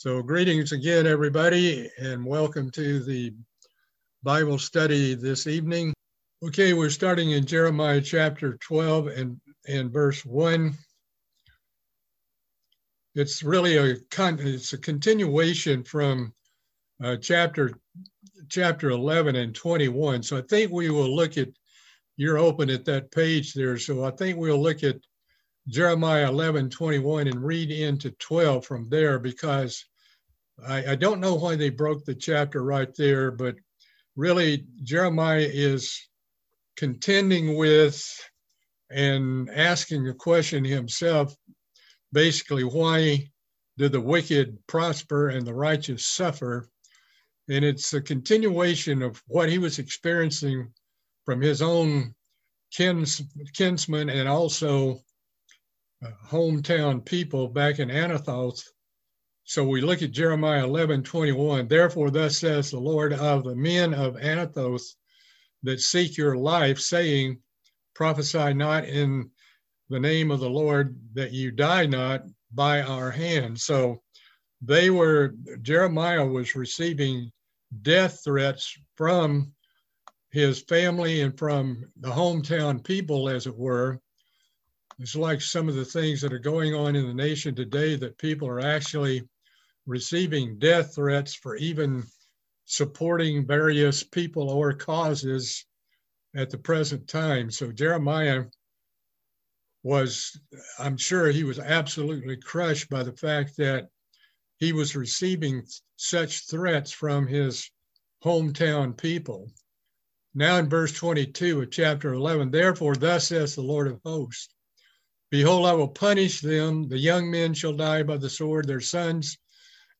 Part 8 of a Bible Study series on the book of Jeremiah.